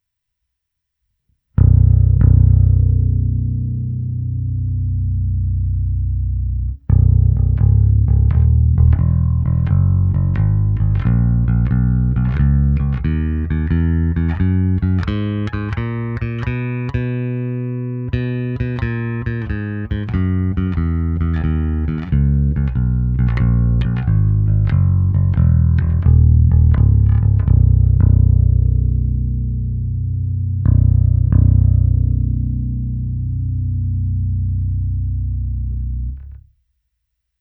Co dobře? Skvěle! Aktuální ukázka na všech strunách, v tomto případě přes Zoom MS-60B: